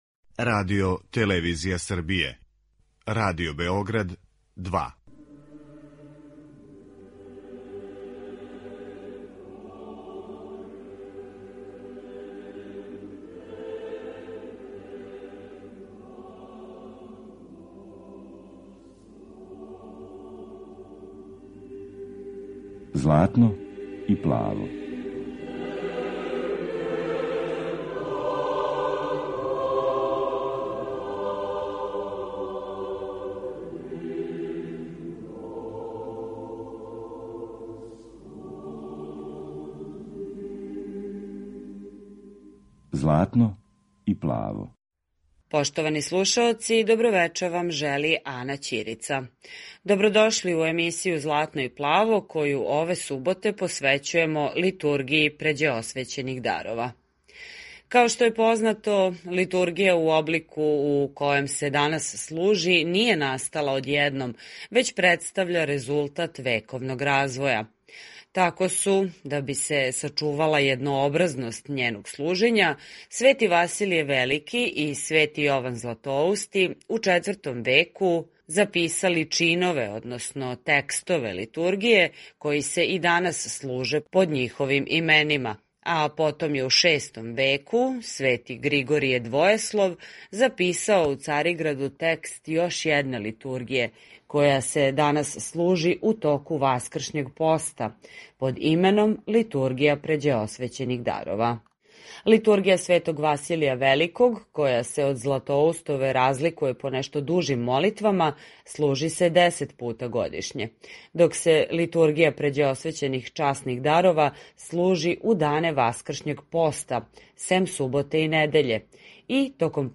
Eмисија православне духовне музике